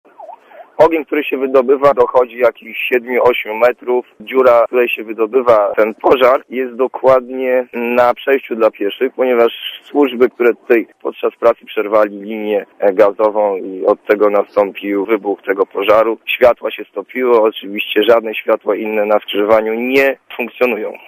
Dla Radia ZET mówi świadek zdarzenia